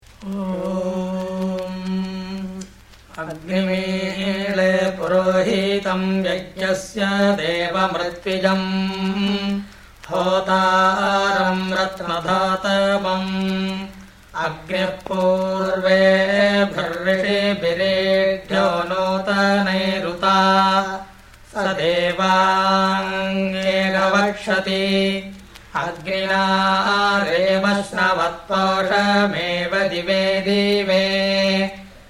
Samhitapātha ("continuous recitation") with accents marked by pitch change using three pitches.